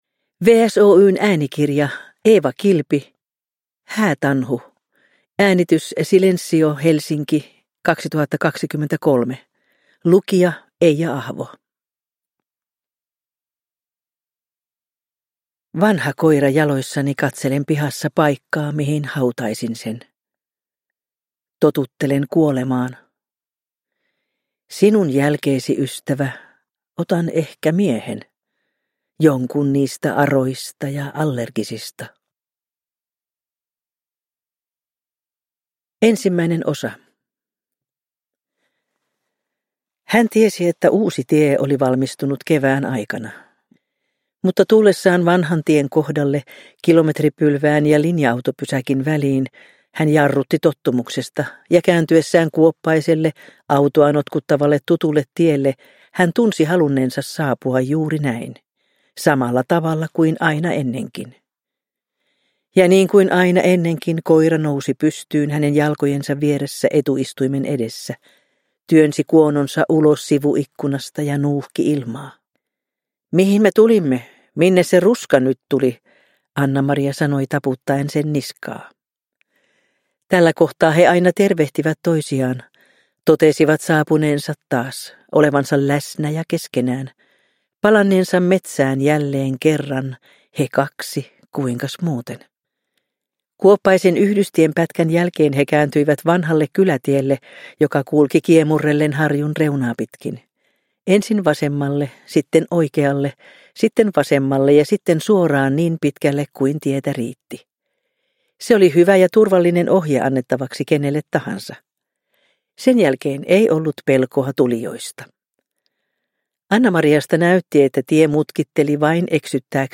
Häätanhu – Ljudbok – Laddas ner